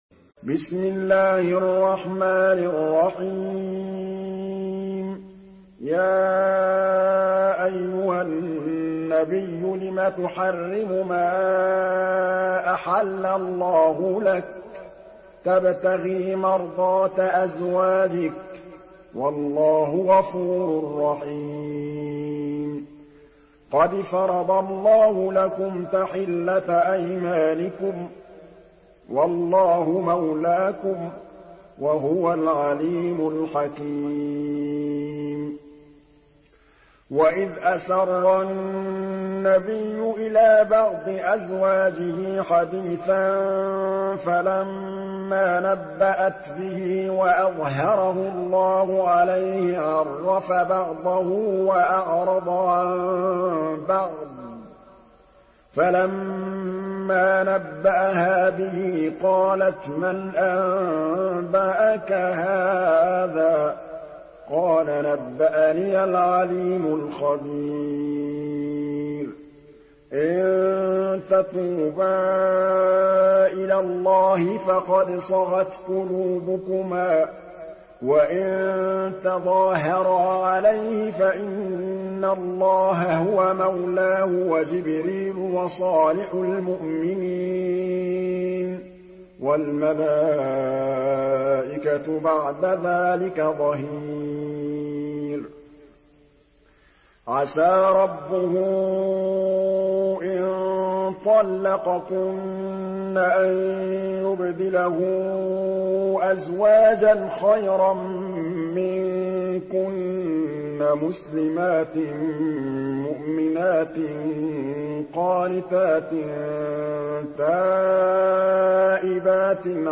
Surah আত-তাহরীম MP3 by Muhammad Mahmood Al Tablawi in Hafs An Asim narration.
Murattal Hafs An Asim